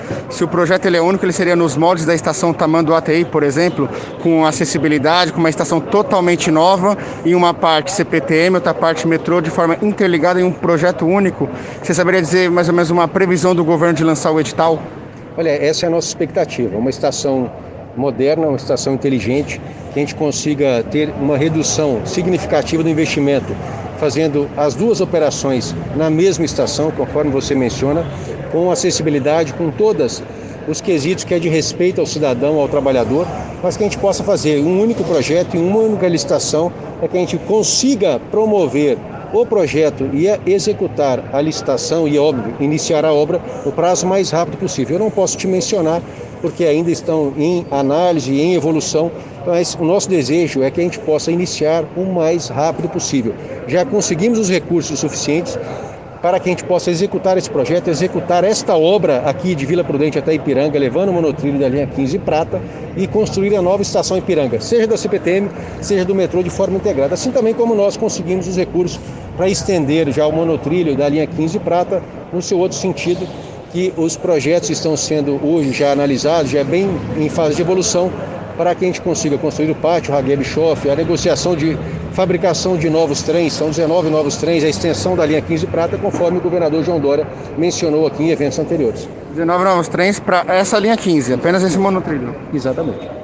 A informação é do secretário dos Transportes Metropolitanos, Alexandre Baldy, durante entrevista para a imprensa nesta terça-feira, 9 de fevereiro de 2021, em resposta a uma pergunta do Diário do Transporte.